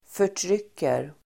Uttal: [för_tr'yk:er]